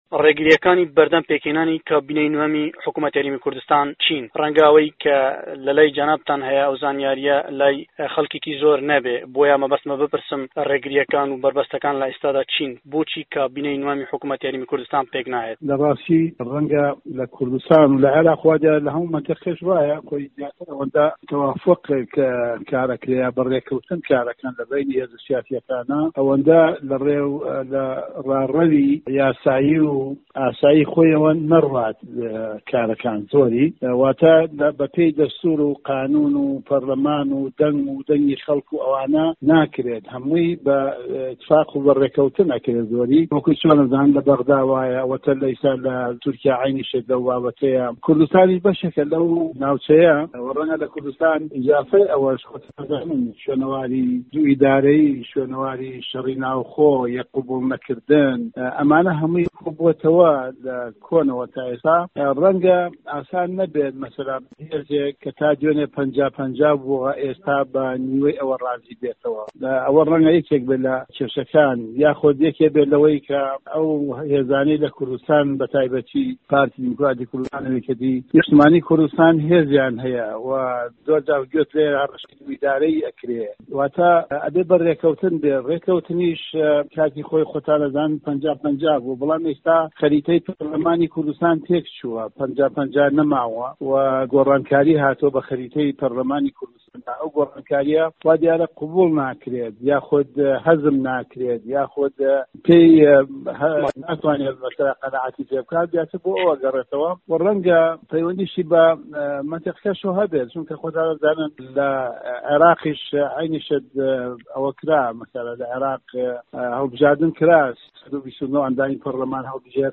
وتوێژ لەگەڵ مەحەمەدی حاجی مەحمود